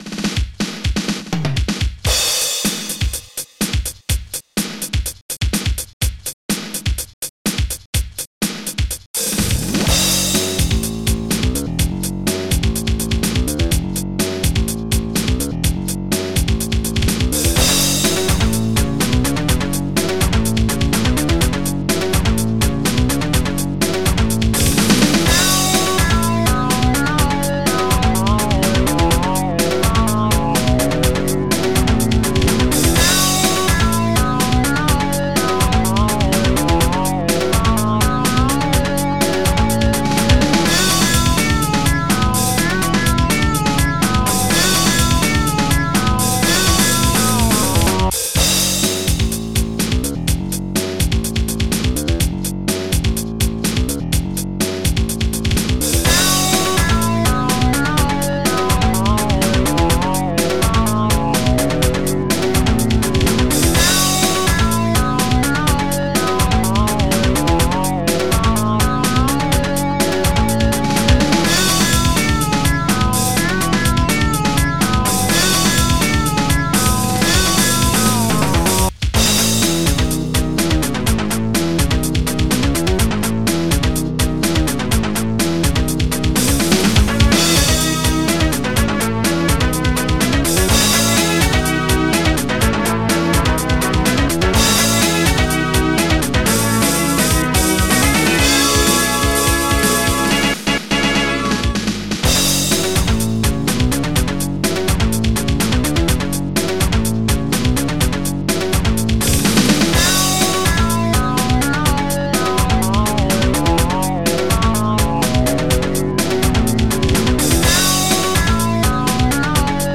s3m (Scream Tracker 3)
Tom
BrassSynth